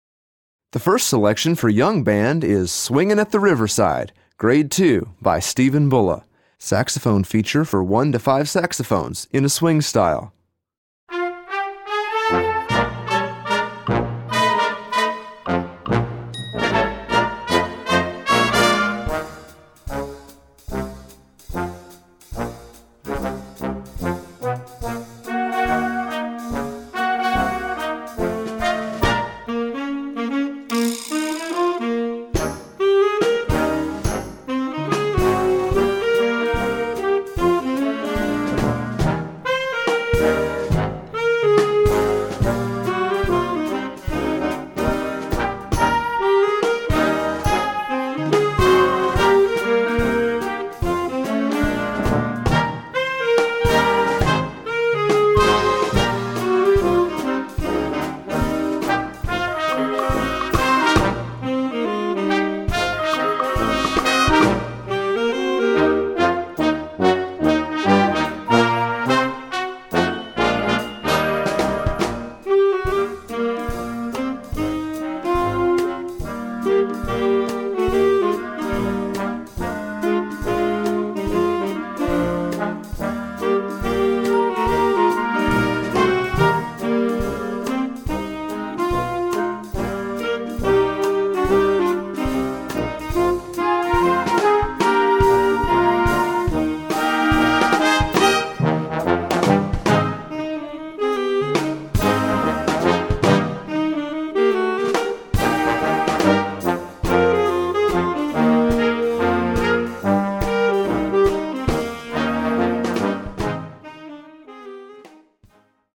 Voicing: Saxophone Section w/ Band